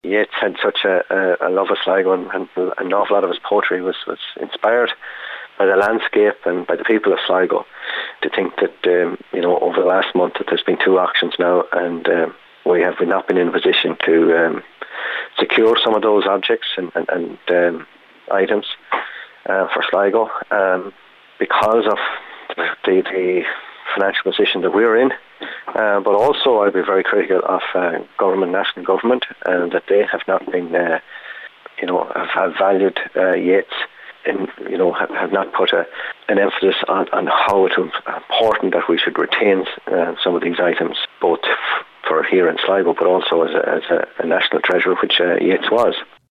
Cllr Hubert Keaney says neither Sligo County Council or the Yeats Society in Sligo has the money to buy such items when they go for public auctions: